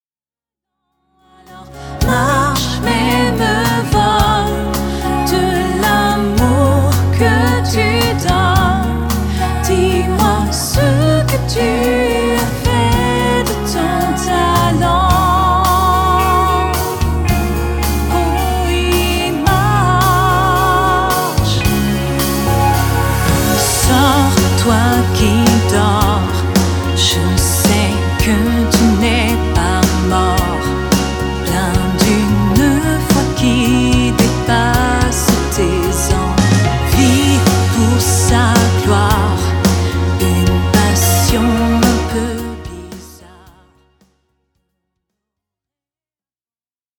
Dans un style pop saupoudré de musique du monde